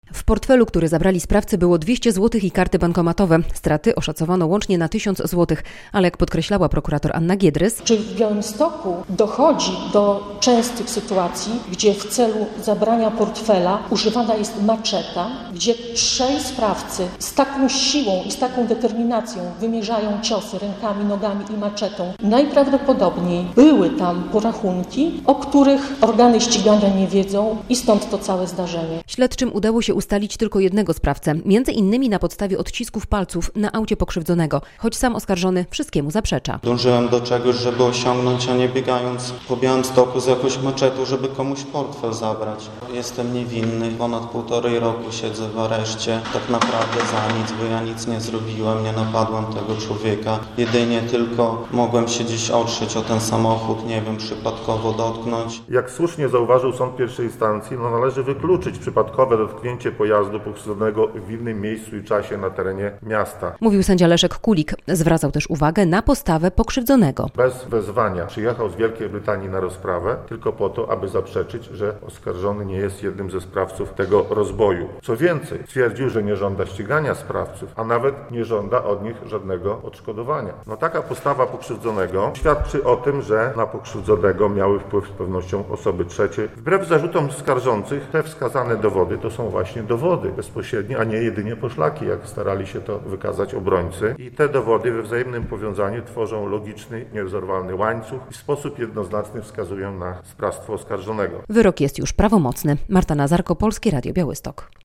Prawomocny wyrok za udział w napadzie z użyciem maczety - relacja